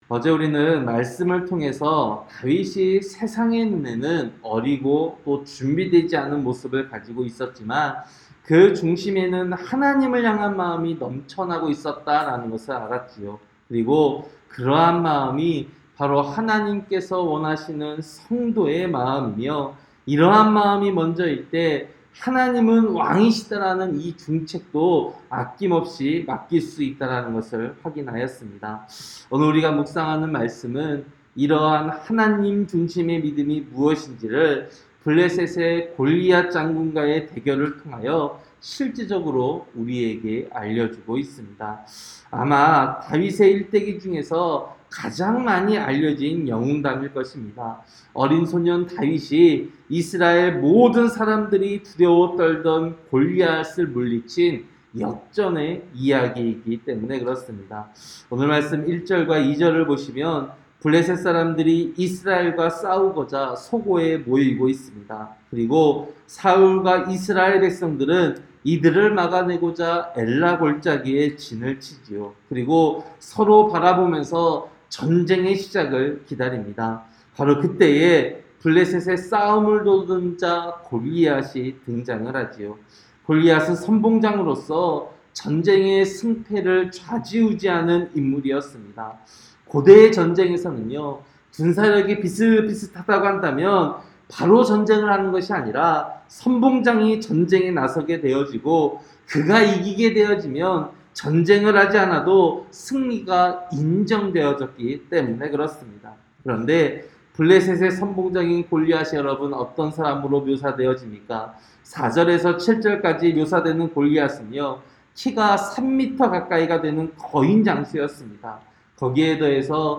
새벽설교-사무엘상 17장